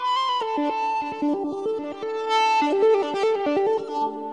描述：一个几乎类似于长笛的吉他样本，使用惊人的Ebow gizmo和我的Epiphone Les Paul吉他通过Marshall放大器制作。添加了一些混响。
标签： 环境 气氛 ebow 电子 吉他 音乐 处理
声道立体声